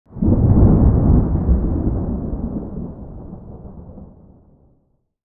pre_storm_4.ogg